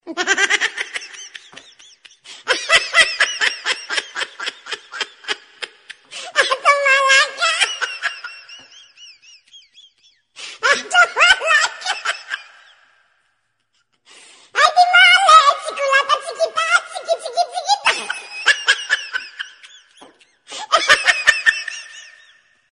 Tải âm thanh "hahhaha" - Hiệu ứng âm thanh tiếng cười